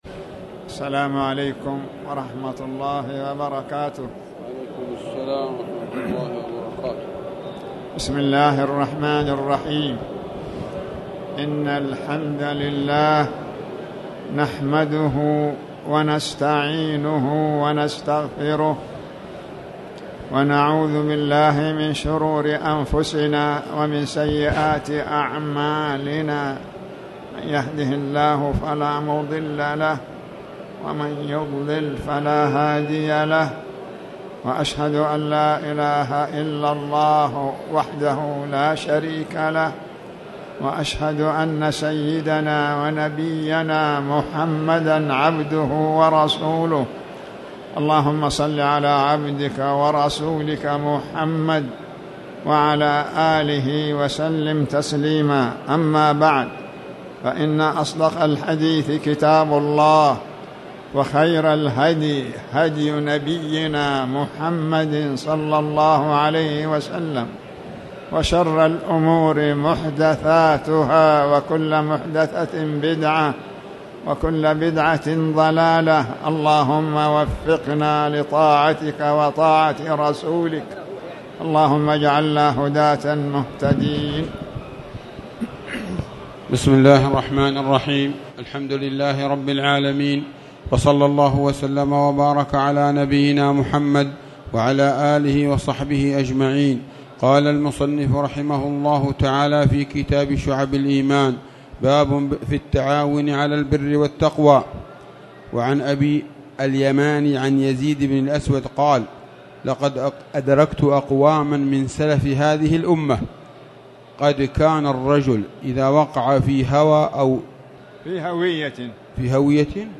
تاريخ النشر ٢٩ شوال ١٤٣٨ هـ المكان: المسجد الحرام الشيخ